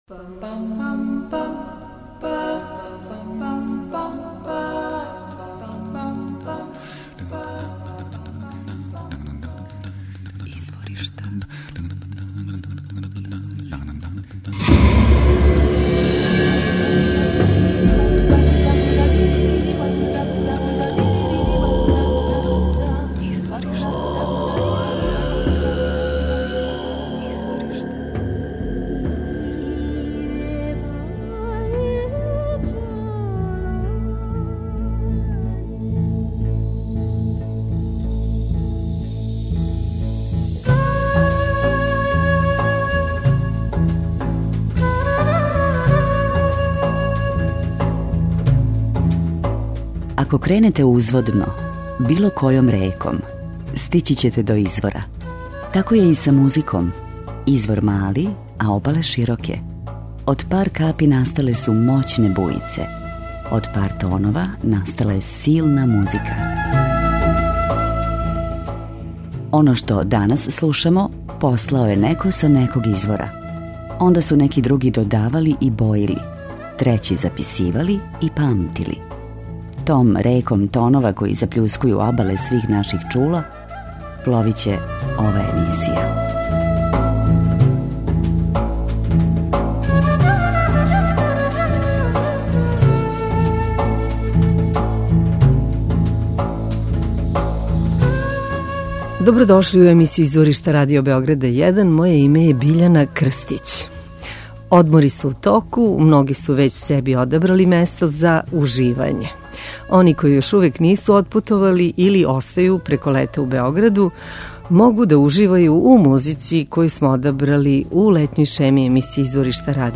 Одмори су у току, многи су већ себи одабрали место за уживање. Они који још увек нису отпутовали или остају преко лета у Београду моћи ће да уживају у врелим ритмовима и мелодијама из читавог света које смо одабрали у летњој шеми емисије Изворишта.